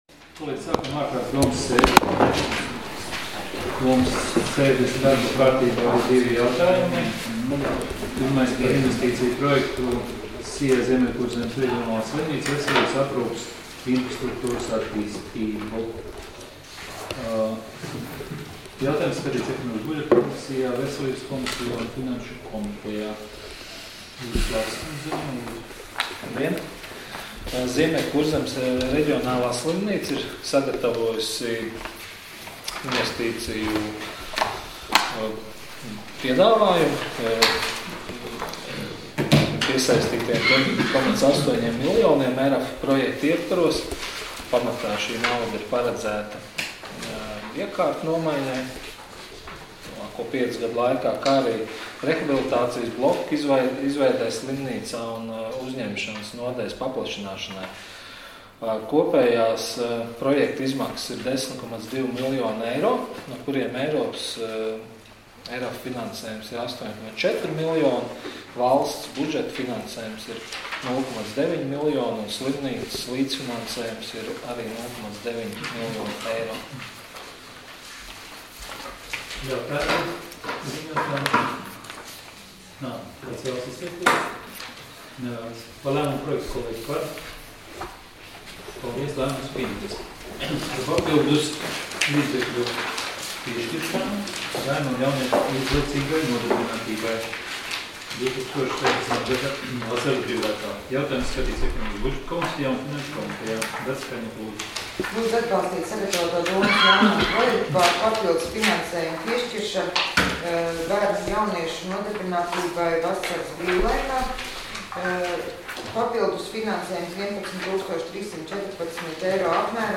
Domes sēdes 26.05.2017.audioieraksts